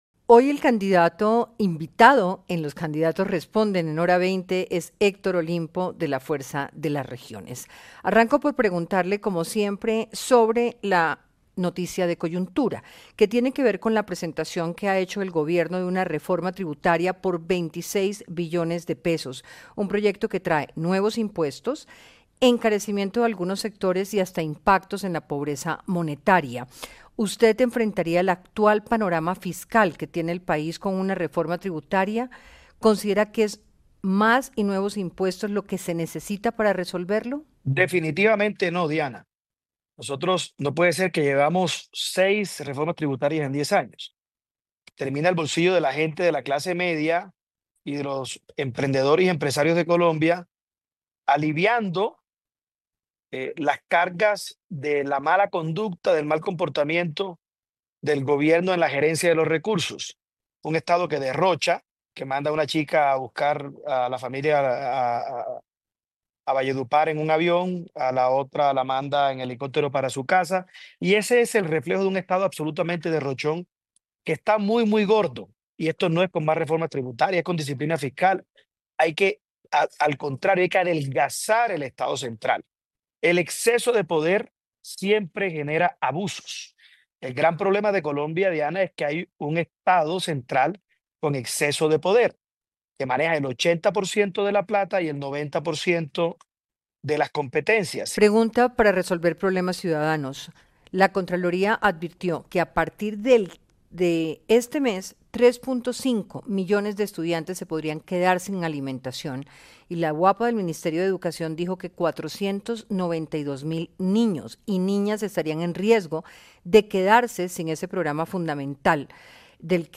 En diálogo con Hora20 de Caracol Radio, el precandidato presidencial, Héctor Olimpo Espinosa, comentó ante la llegada de una nueva tributaria, que esa no es la vía para solucionar los problemas fiscales del país, “no puede ser que llevamos seis reformas tributarias en diez años.